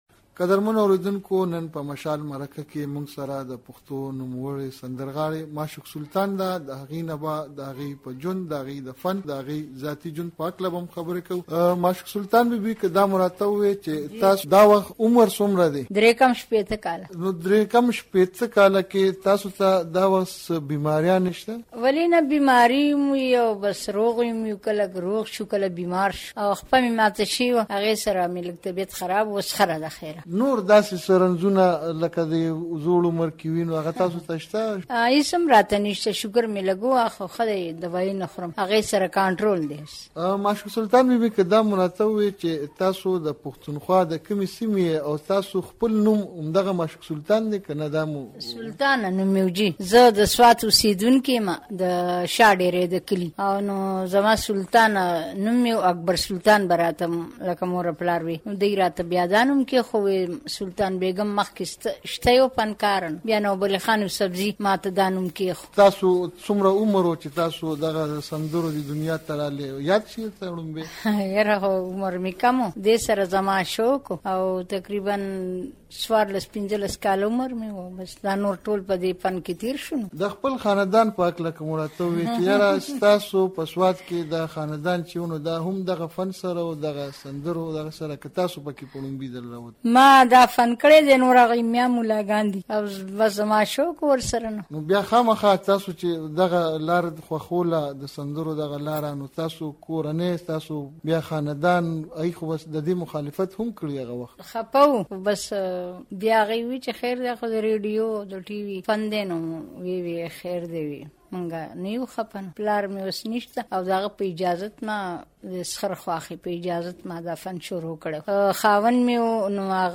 له خوږ ږغې سندرغاړې معشوق سلطانه سره یو څو خبرې